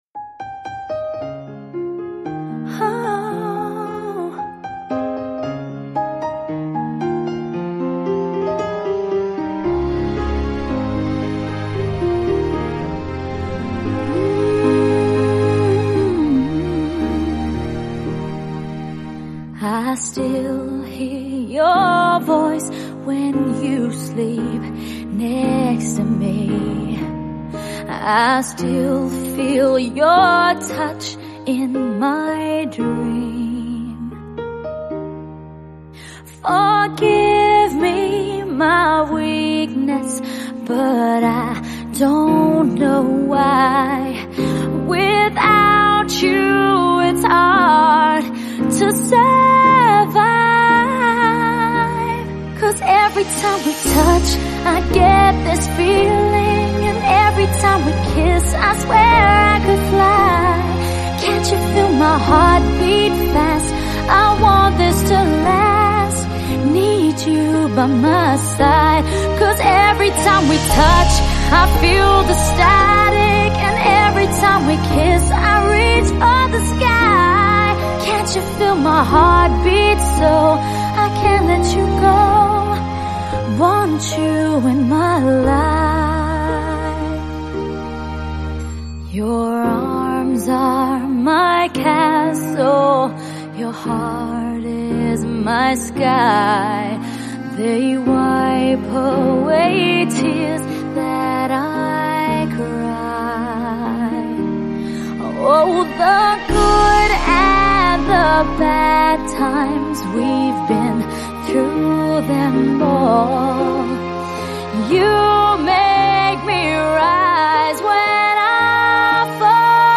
Dance-Eletronicas